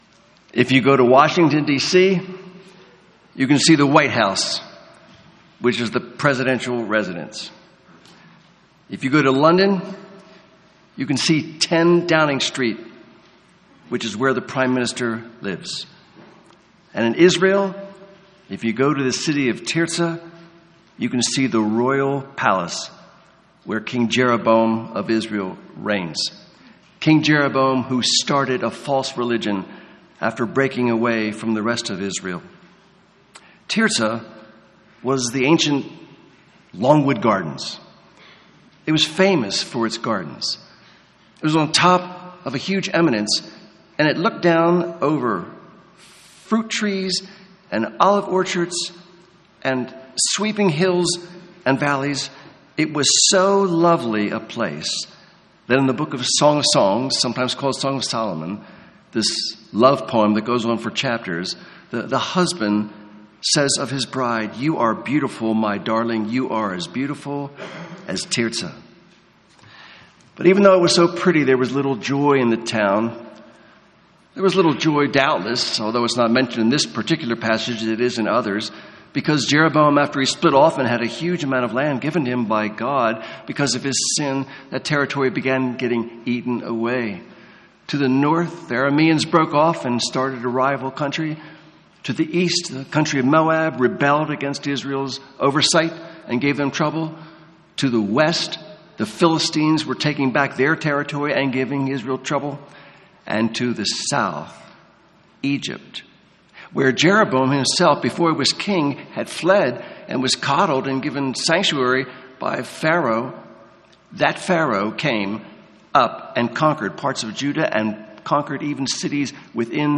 1 Kings — Audio Sermons — Brick Lane Community Church